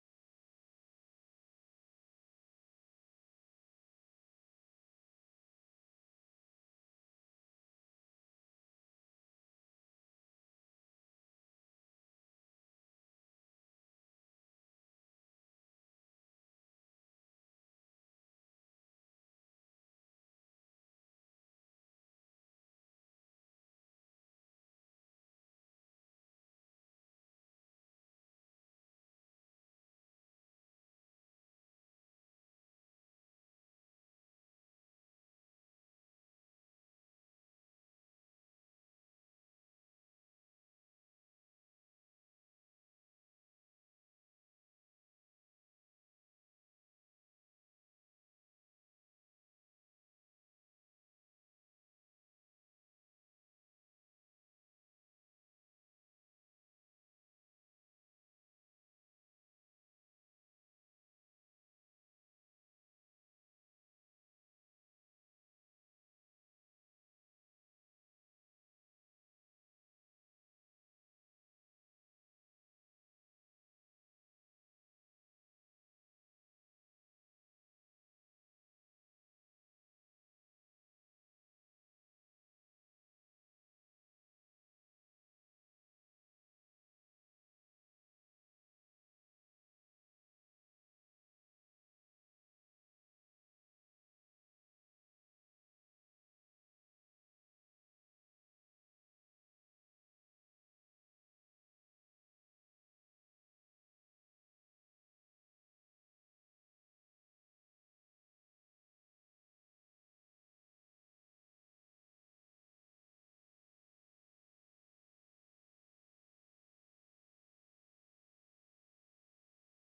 Jesus only -The Word of God Sermon
Jesus-only-The-Word-of-God-Sermon-Audio-CD.mp3